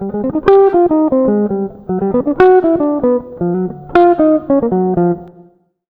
160JAZZ  5.wav